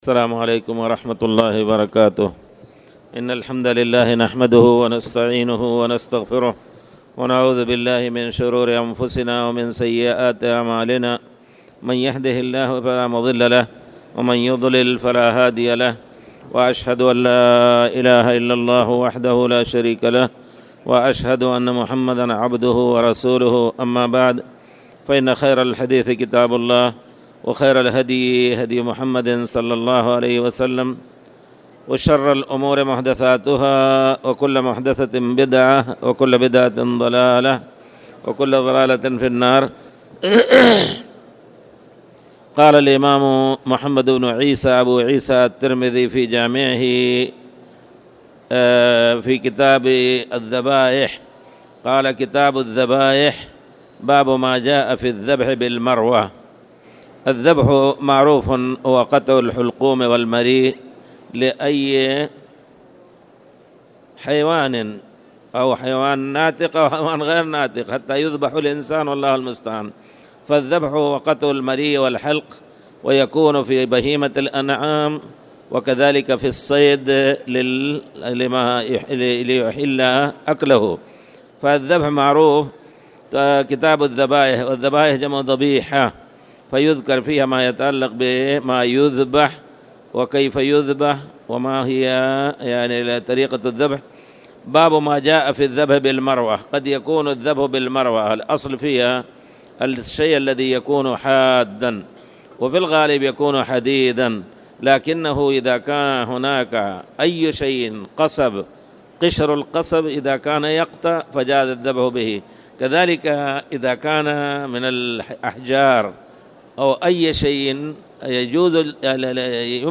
تاريخ النشر ١٦ جمادى الآخرة ١٤٣٨ هـ المكان: المسجد الحرام الشيخ